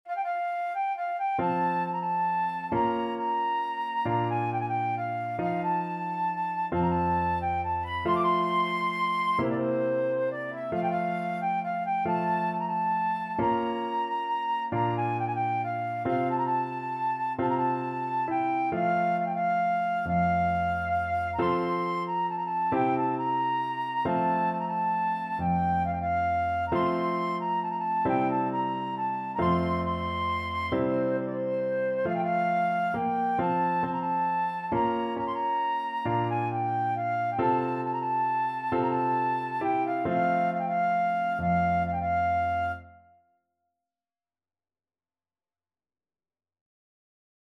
World Trad. Joc In Patru (Romanian Folk Song) Flute version
Traditional Music of unknown author.
F major (Sounding Pitch) (View more F major Music for Flute )
One in a bar .=45